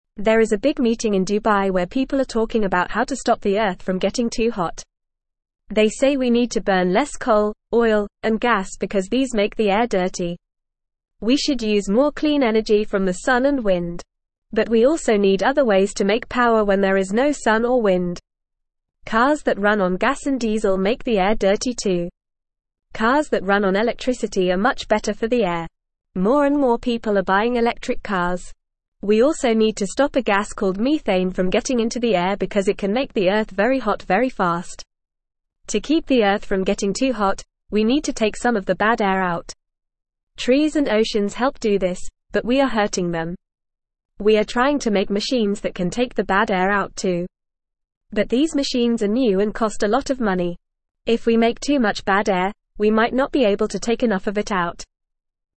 Fast
English-Newsroom-Lower-Intermediate-FAST-Reading-Ways-to-Help-Our-Planet-and-Clean-the-Air.mp3